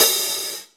paiste hi hat5 half.wav